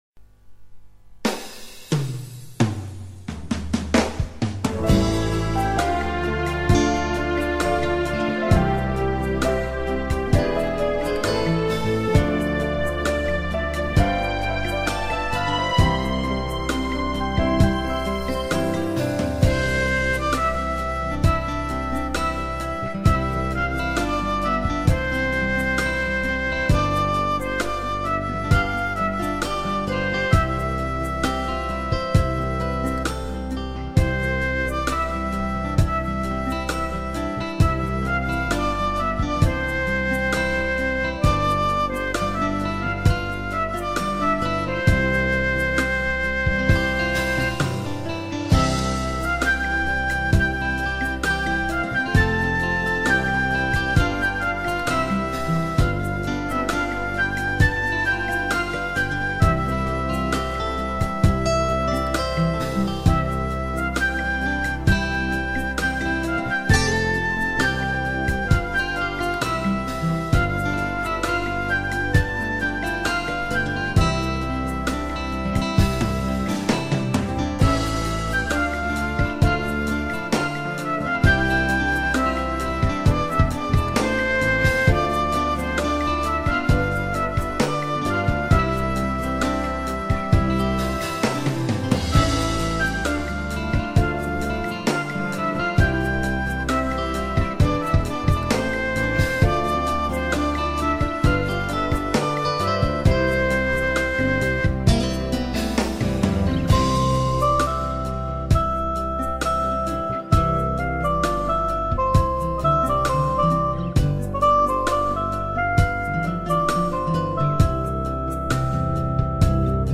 באורגנית.
הכלים בהקלטה:חליל,סקסופון סופרן,גיטרה, פסנתר וכינור.
העיבוד ובחירת הכלים חביב, אבל - Syntersizerי מדי.
העיבוד היה "סינטיסייזרי" מדי (ולא, אין לי אוזן מקצועית :))
אם זה עוזר לך אז הדגם הוא: ROLAND E-60